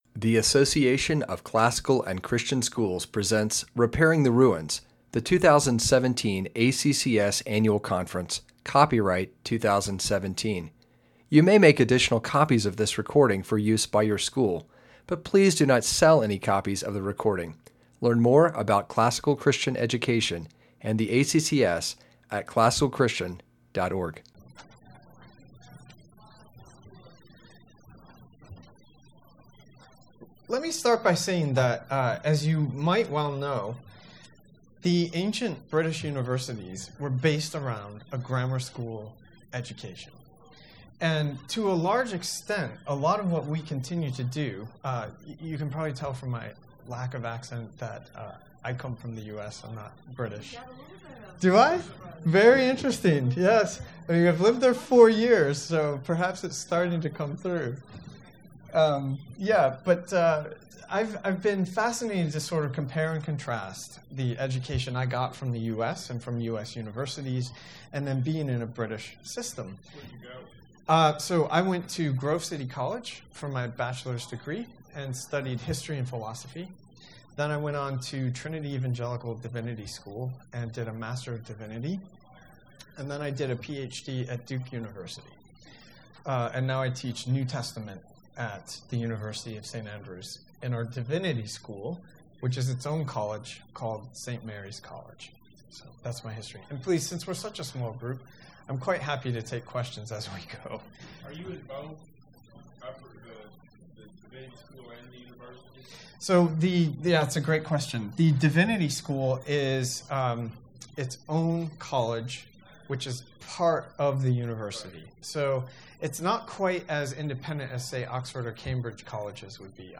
2017 Workshop Talk | 0:57:40 | All Grade Levels, Culture & Faith
This session will examine some of the pros and cons of moving from a classical Christian education to university studies in the land of Lewis and Tolkien. Speaker Additional Materials The Association of Classical & Christian Schools presents Repairing the Ruins, the ACCS annual conference, copyright ACCS.